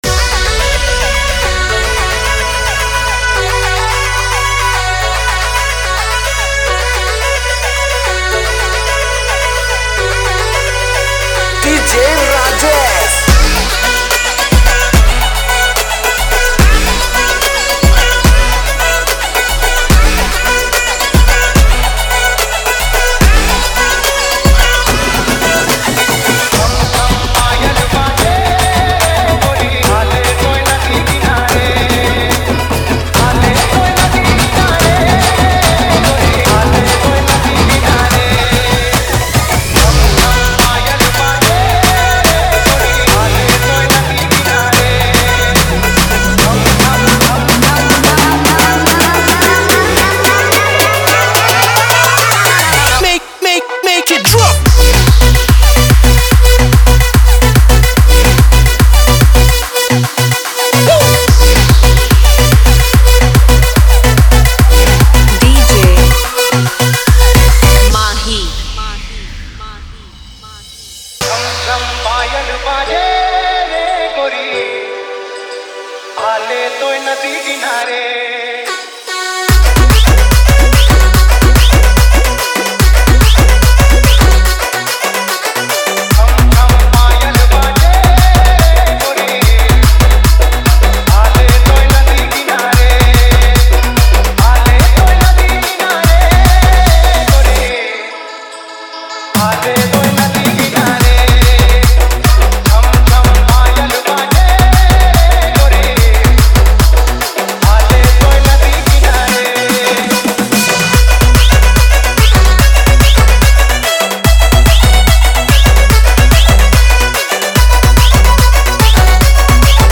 Category: Holi Special Odia Dj Remix Songs